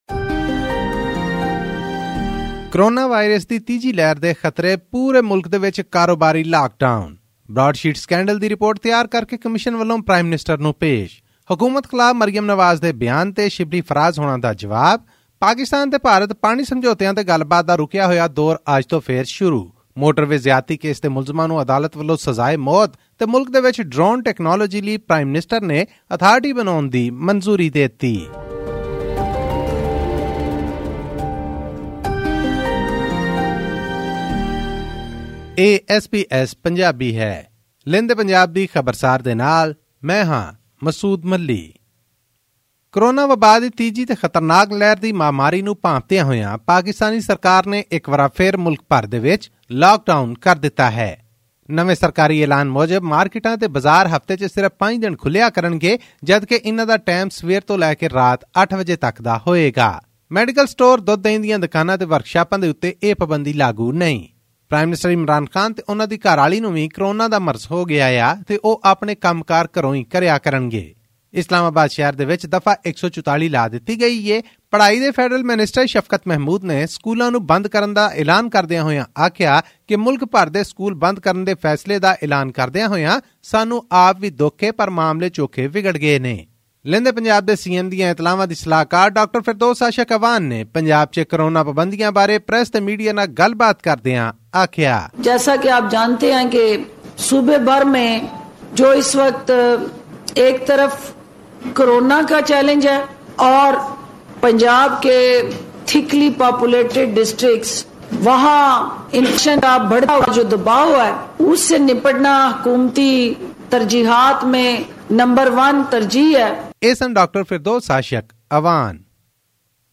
All this and more in our weekly news segment from Pakistan.